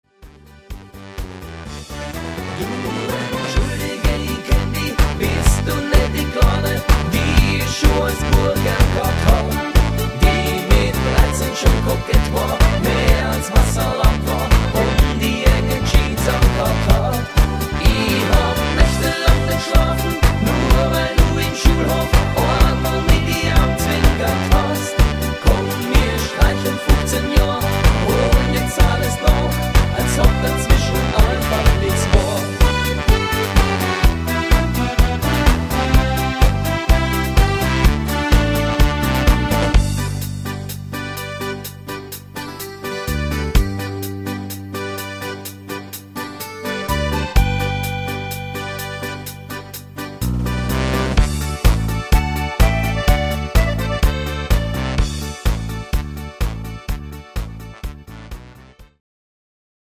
Hütten-Mix